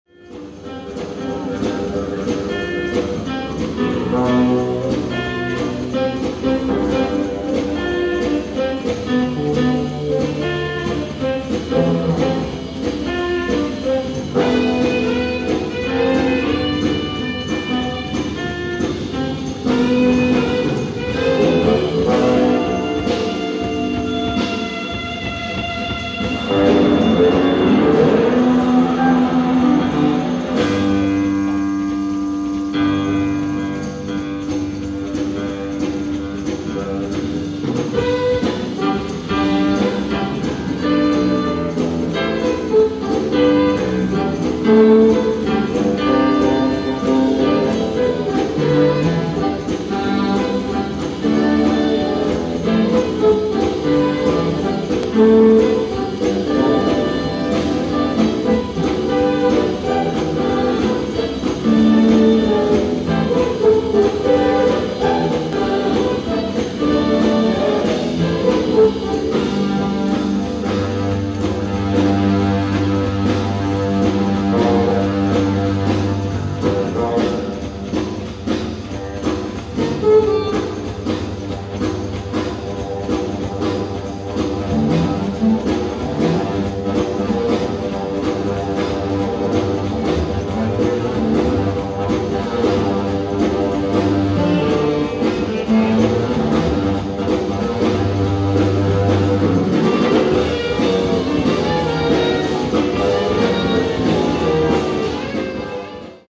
ライブ・アット・ハマースミス・オデオン、ロンドン 11/14/1980